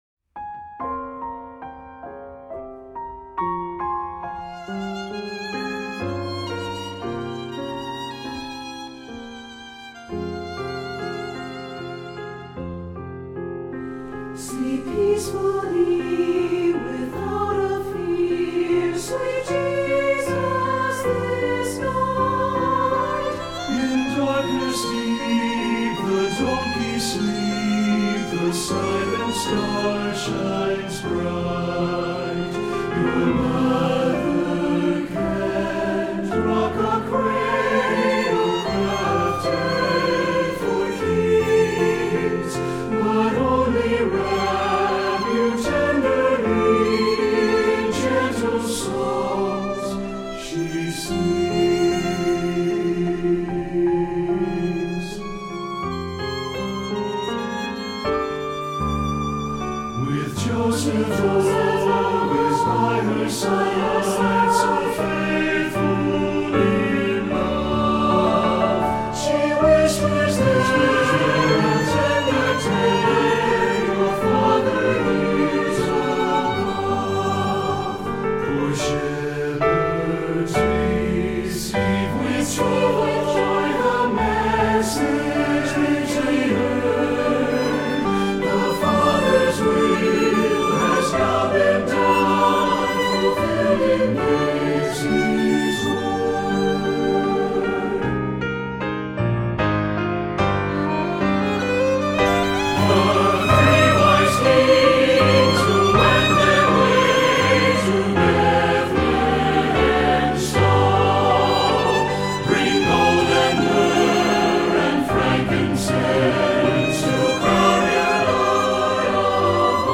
Voicing: SATB and Violin